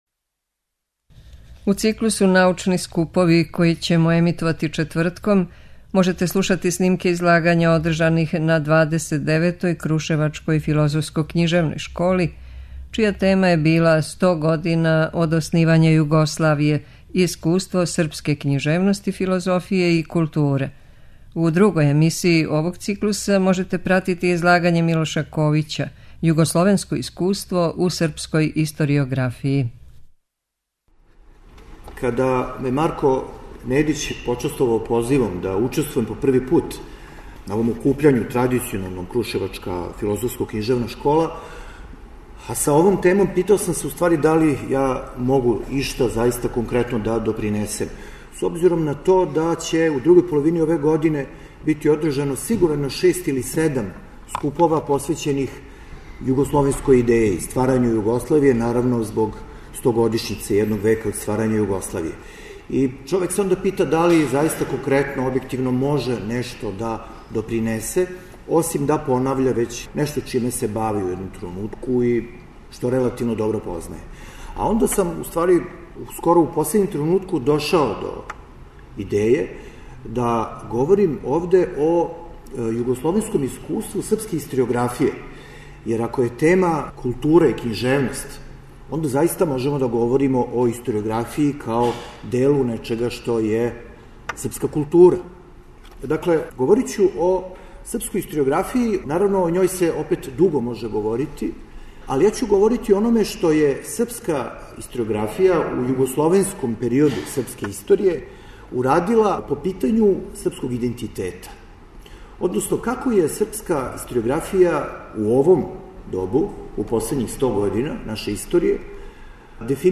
Крушевачка филозофско-књижевна школа ове године је одржана 22. и 23. јуна у Свечаној сали Скупштине града Крушевца. Учесници овог научног скупа су из филозофске, социолошке, историјске, антропoлошке и књижевнотеоријске перспективе разматрали какав су утицај на српску културу и идентитет имали југословенство и Југославија као држава, почев од краљевине преко социјалистичке републике до њеног разбијања и нашег времена.